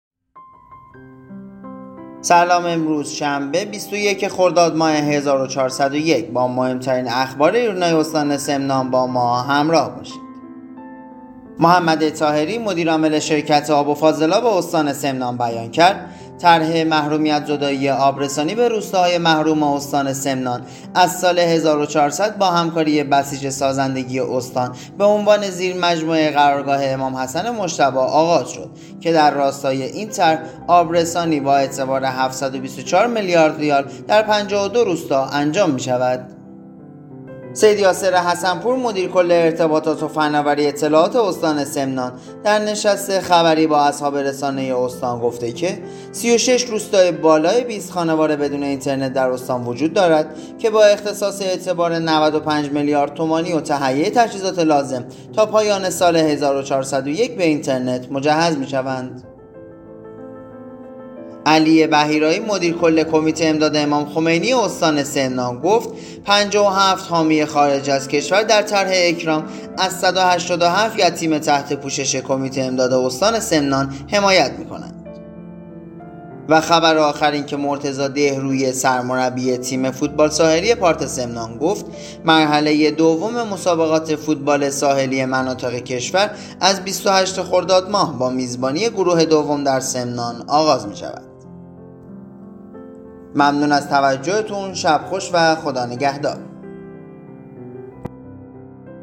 صوت | اخبار شبانگاهی ۲۱ خرداد استان سمنان